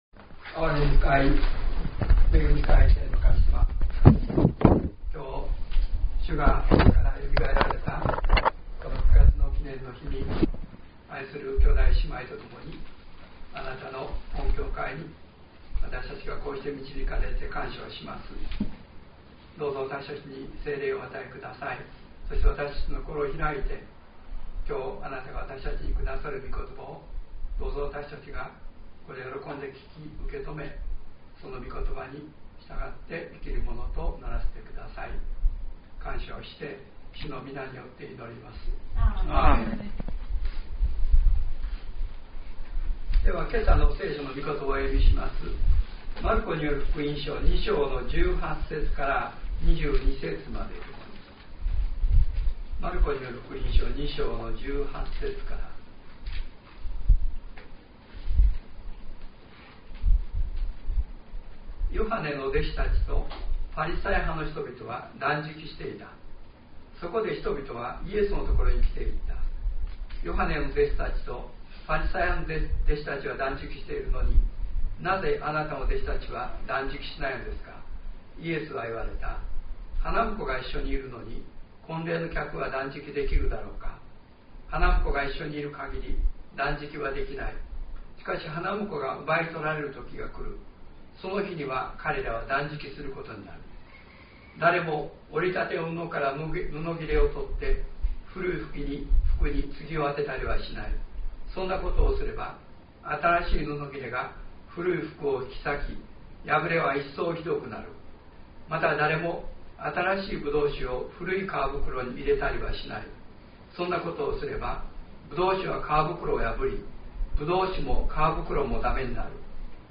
西谷伝道所朝拝 マルコ福音書２章１３節－１７節「救われるのは誰か」2021,7,18